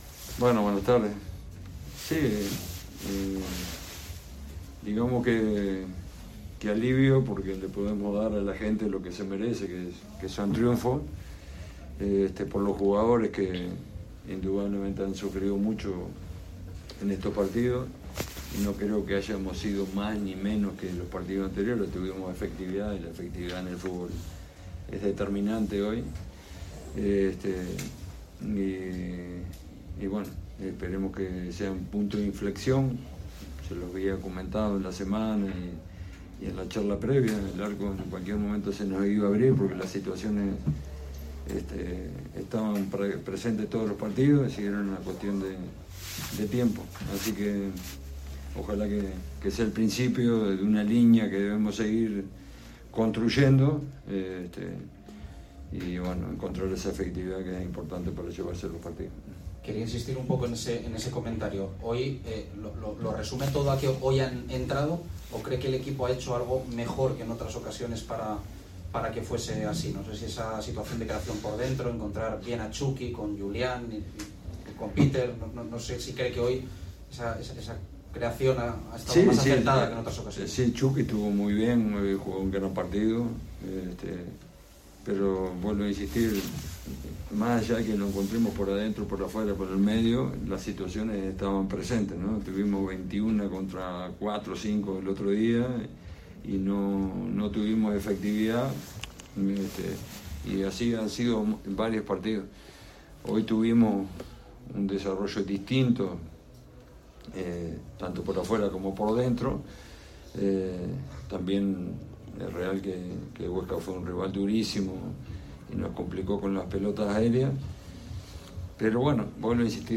aquí la rueda de prensa completa